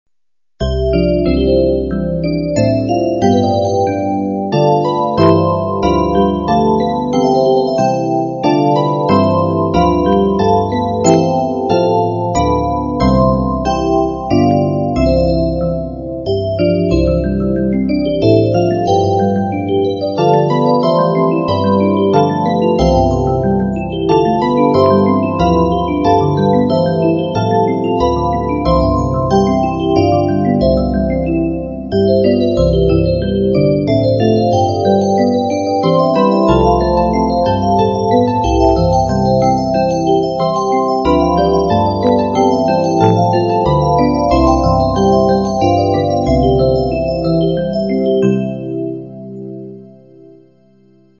[Computer Simulation]